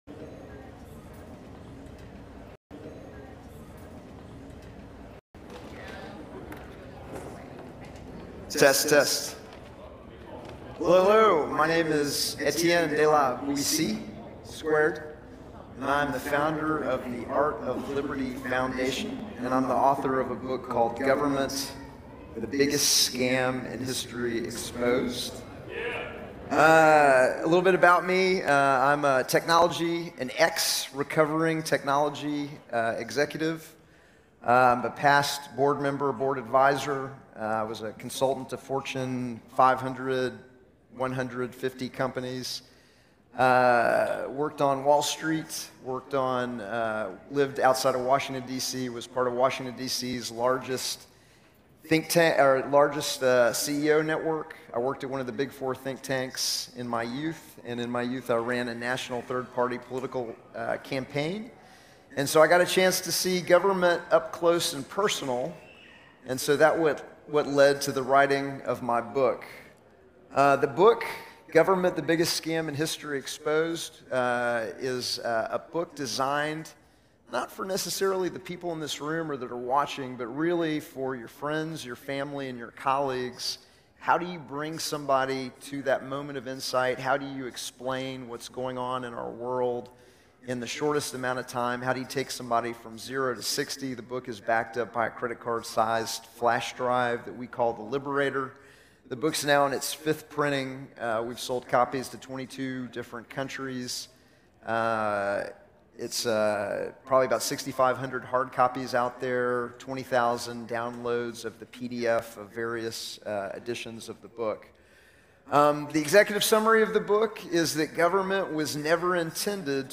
@ C.A.U.S.E Fest 2023